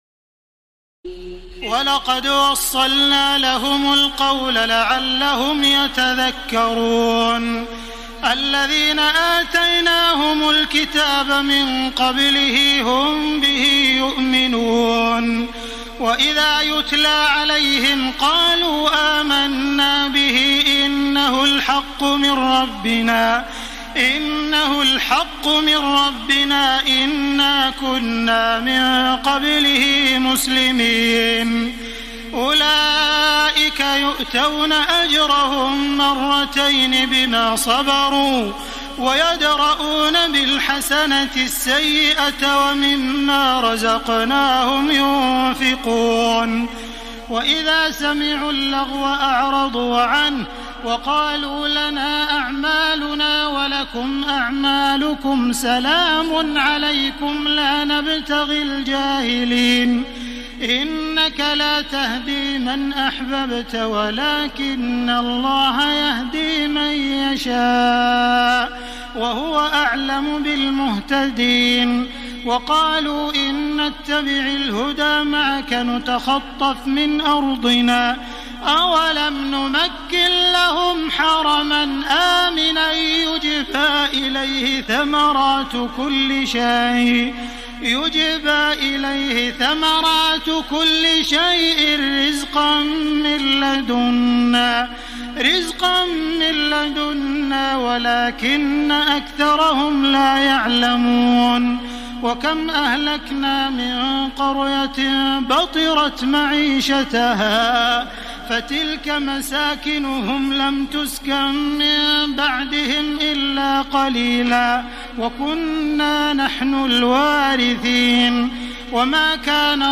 تراويح الليلة التاسعة عشر رمضان 1433هـ من سورتي القصص (51-88) والعنكبوت (1-45) Taraweeh 19 st night Ramadan 1433H from Surah Al-Qasas and Al-Ankaboot > تراويح الحرم المكي عام 1433 🕋 > التراويح - تلاوات الحرمين